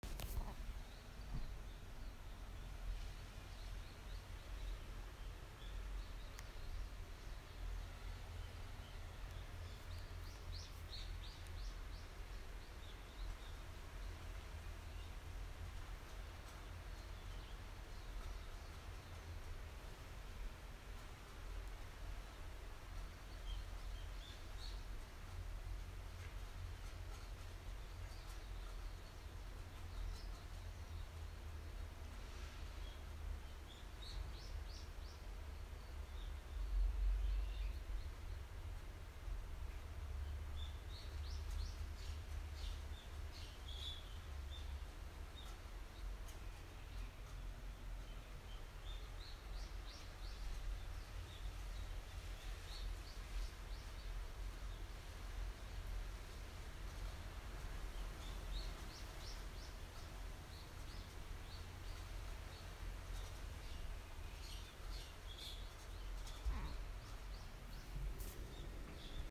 바람소리와 새소리를 들으니 제주도에 온 것이 실감 난다.
복불복의 확률로 짖는 개소리를 포함해 가끔 들리는 사람의 목소리를 제외하면 자연의 소리로만 채워지는 공간 같다.